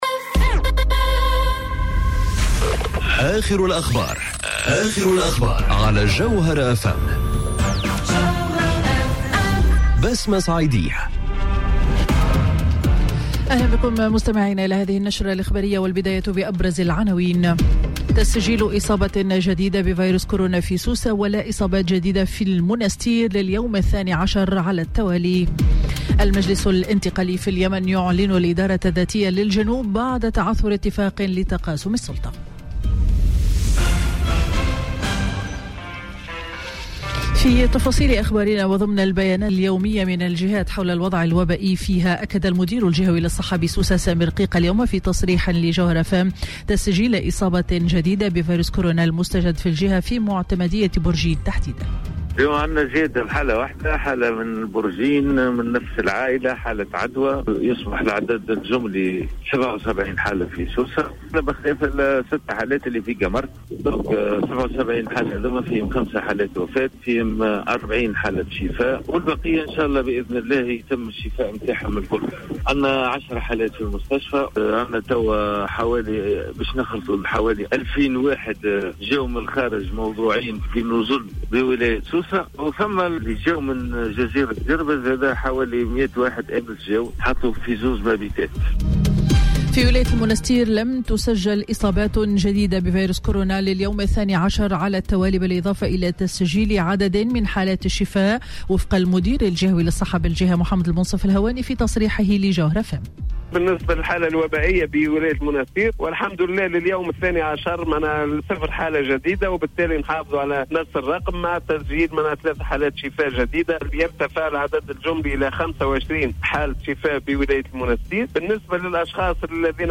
نشرة أخبار منتصف النهار ليوم الأحد 26 أفريل 2020